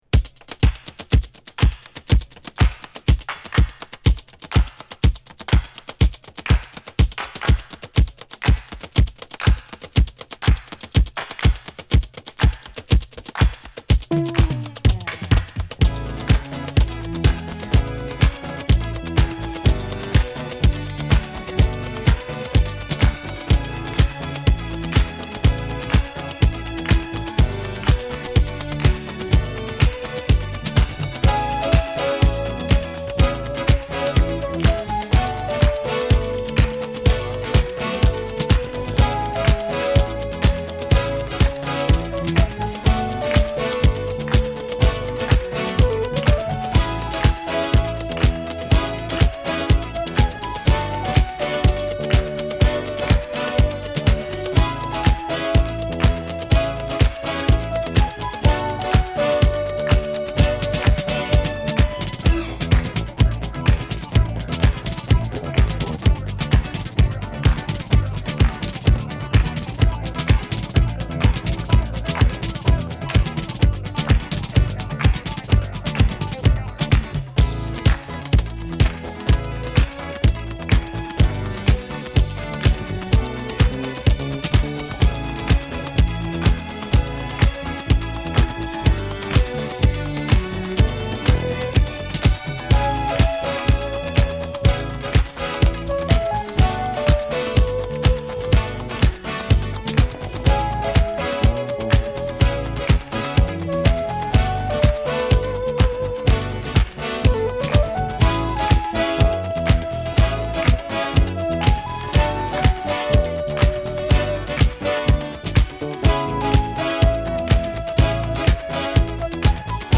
Vocal
Instrumental